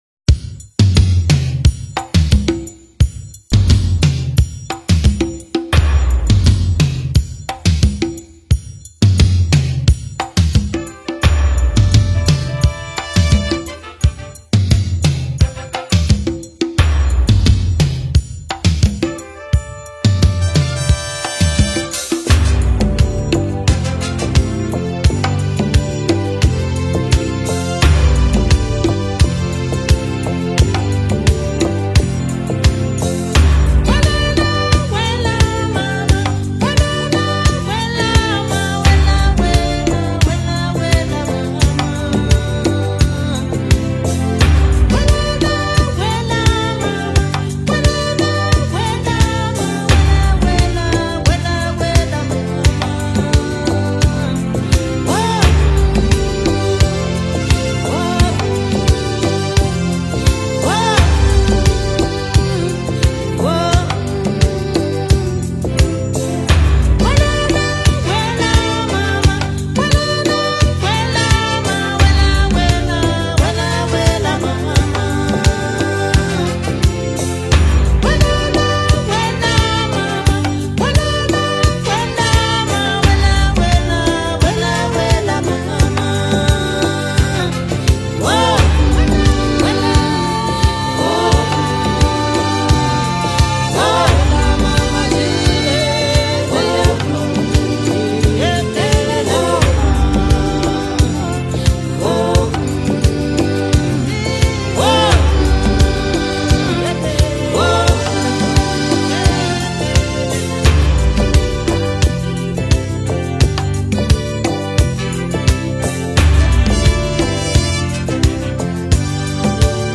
VIBRANT and JOYFUL anthem
African folk and gospel